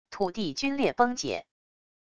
土地龟裂崩解wav音频